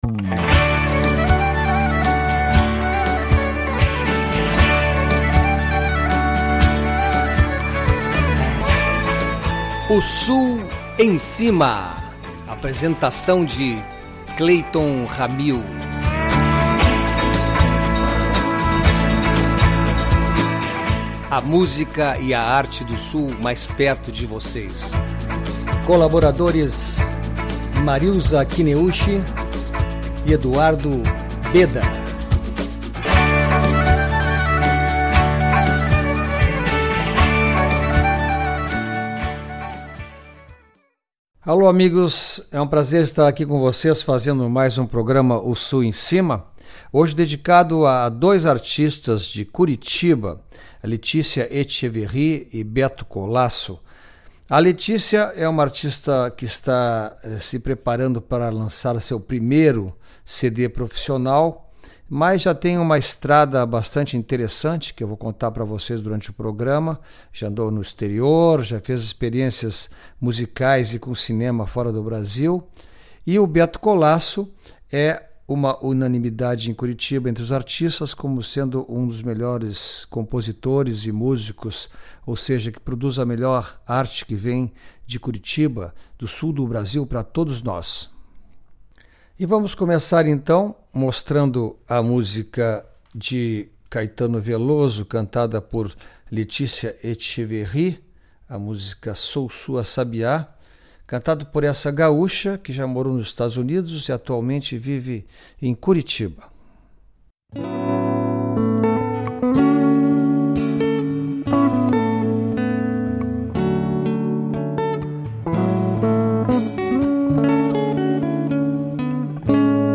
Indiscutível a qualidade, sensualidade e beleza na voz desta grande cantora que com certeza será um grande orgulho para nós gaúchos, já me considero um fã fervorosa desta linda artista.